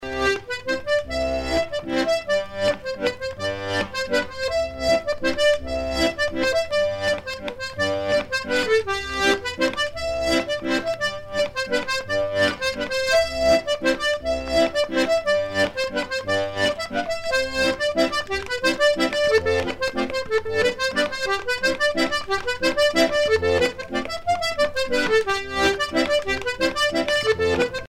danse : scottich trois pas
Pièce musicale éditée